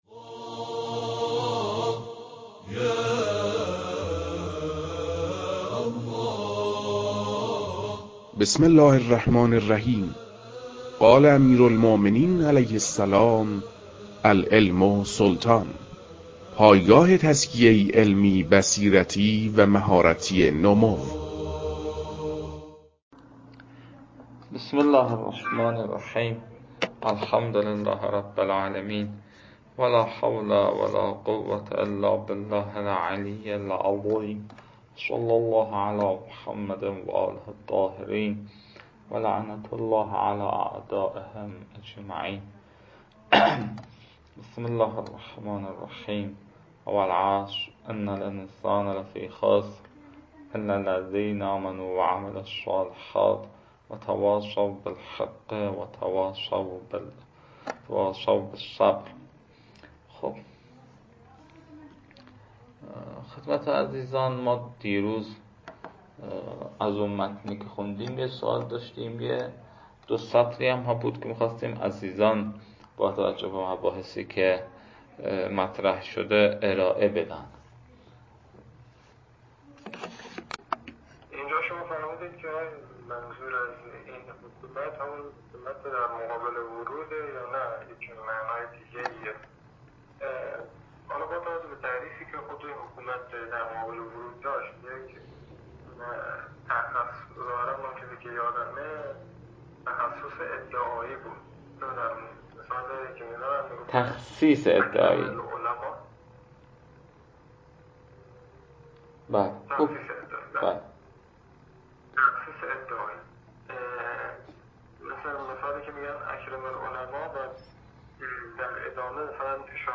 کارگاه تبیین رابطه حدیث سلطنت با ادله انحصار برخی تصرفات در ملک